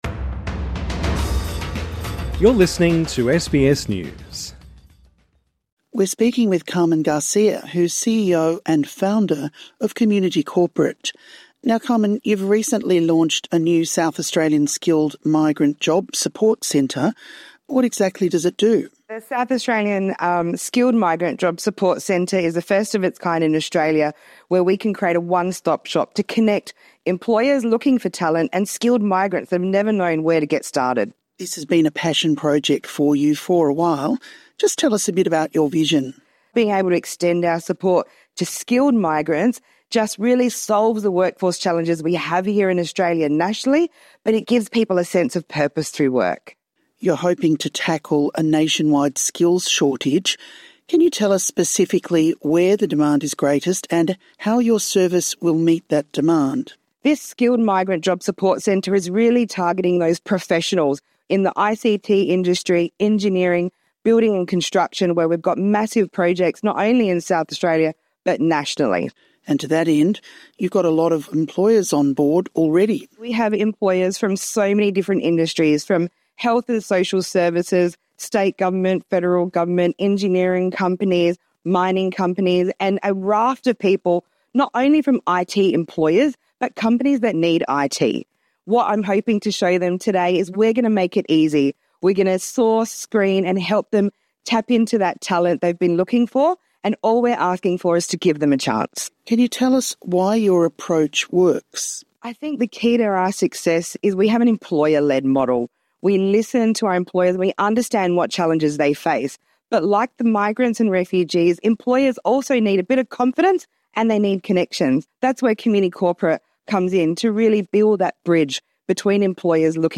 INTERVIEW: Connecting migrants with employers